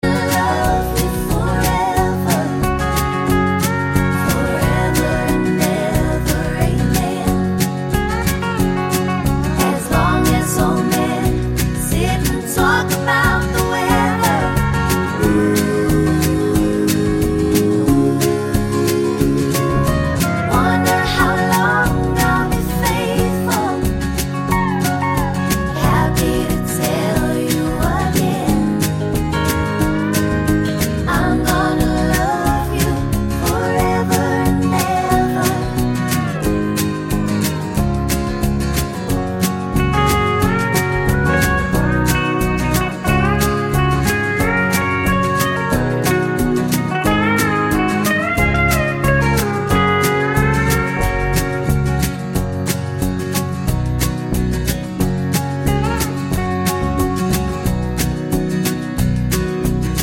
Key of F# Country (Male) 3:33 Buy £1.50